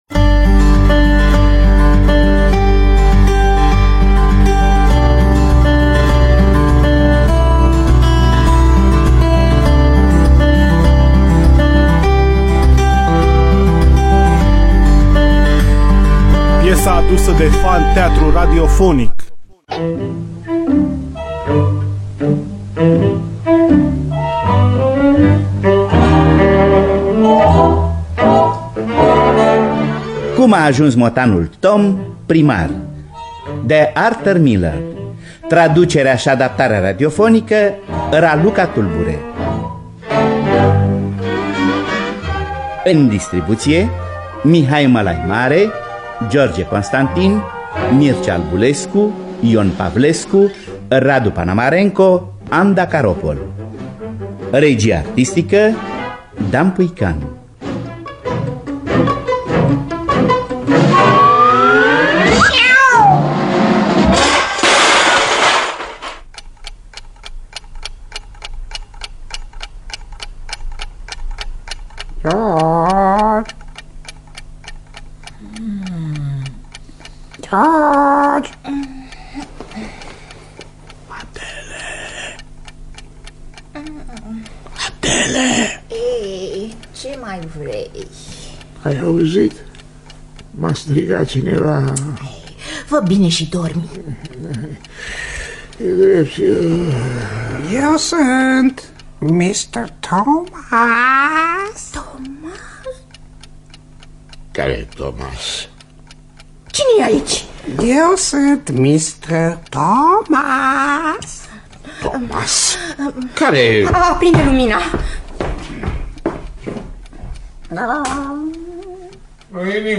Cum a ajuns motanul Tom primar de Arthur Miller – Teatru Radiofonic Online
Înregistrare din anul 1992 (24 octombrie).